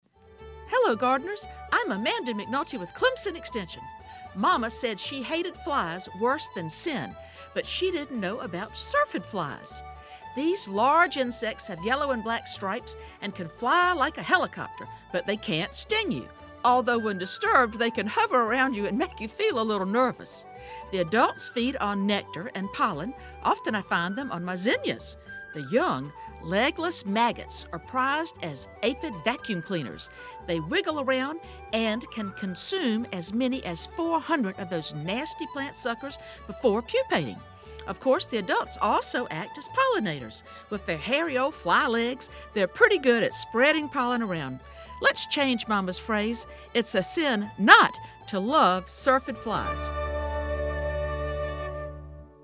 It aired on August 11 and September 11, 2012, on all eight SCETV radio stations, three times each day.